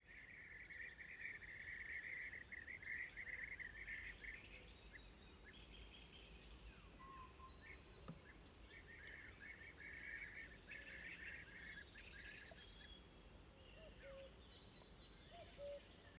Data resource Xeno-canto - Soundscapes from around the world